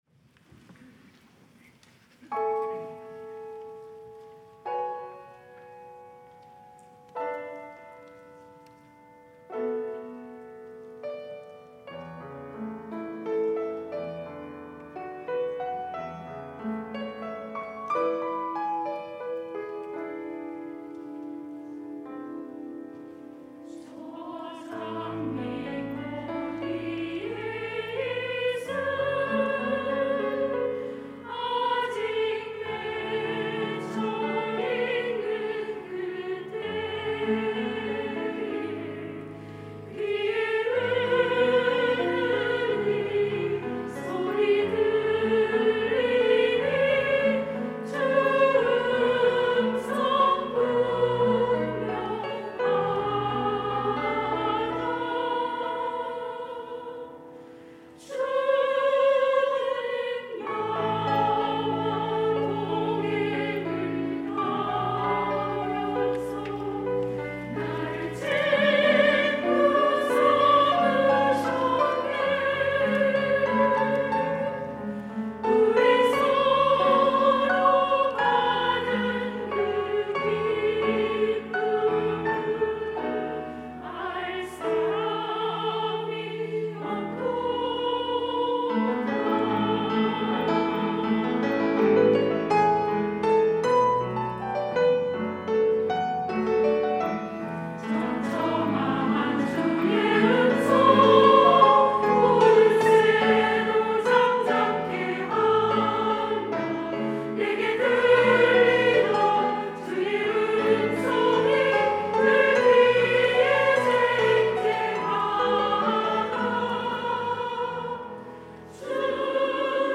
찬양대 여전도회